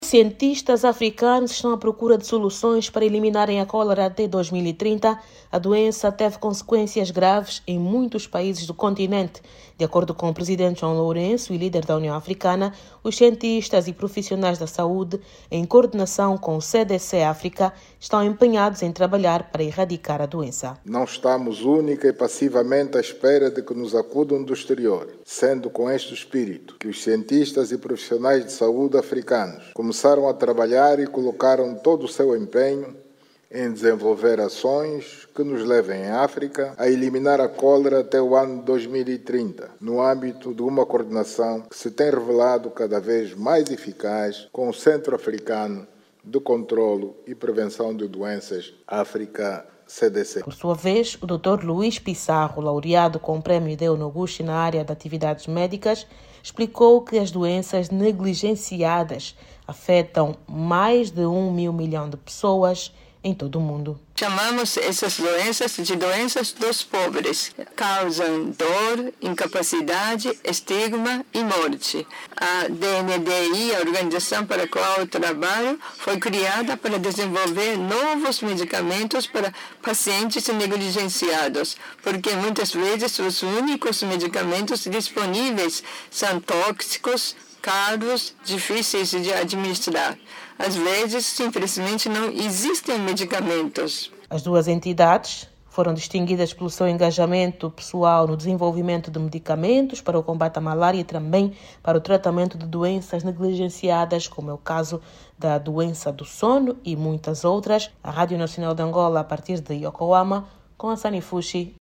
Durante a entrega de prémios IDEO NOGUSHI, o líder da União Africana, deu nota dos esforços para prevenir surtos repetitivos e adopção de medidas de prevenção. Jornalista